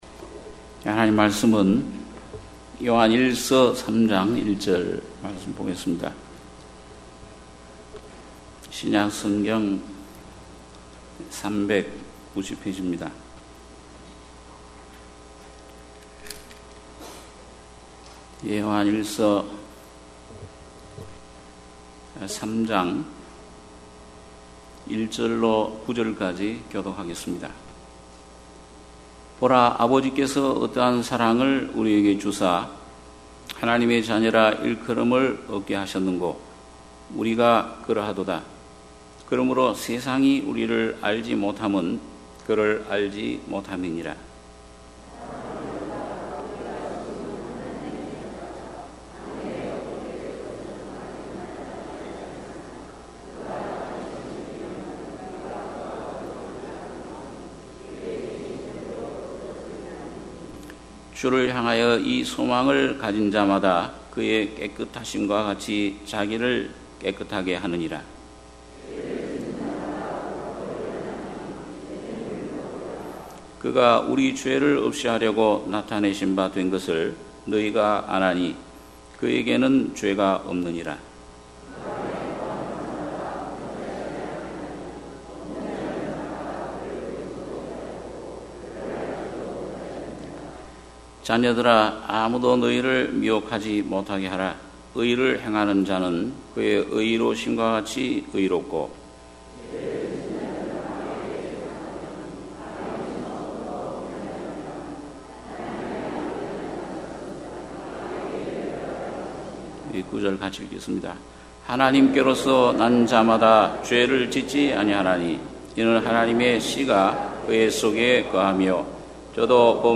주일예배 - 요한일서 3장 1-9절